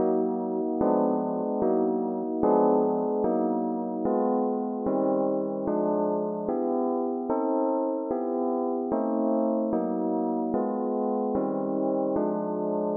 描述：适用于House hiphop lounge chillout或爵士乐的曲目 Rhodes sound organ
Tag: 130 bpm Jazz Loops Piano Loops 322.03 KB wav Key : Unknown